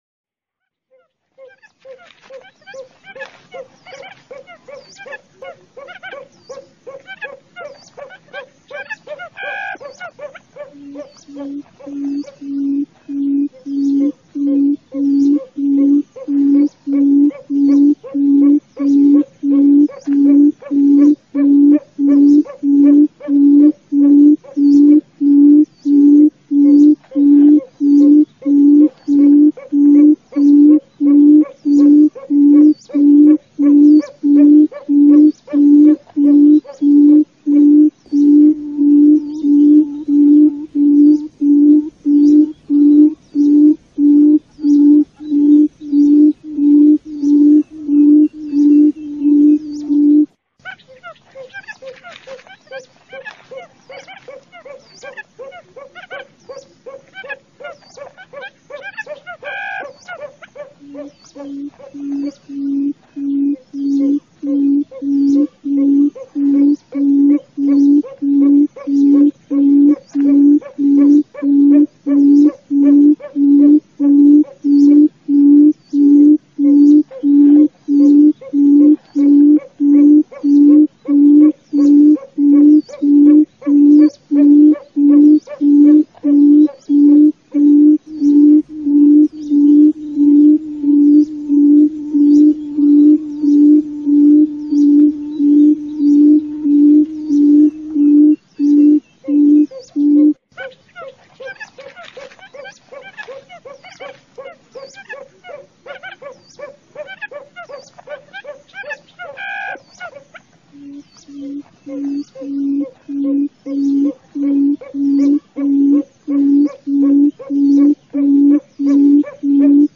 เสียงต่อนกคุ้มอืด ตัวผู้+ตัวเมีย..ร้องหาคู่ mp3
เสียงนกคุ้มอืด (ตัวเมีย) เสียงนกคุ้มอืดตัวผู้, โหลด เสียงนกคุ้มอืด ชัด 100%
หมวดหมู่: เสียงนก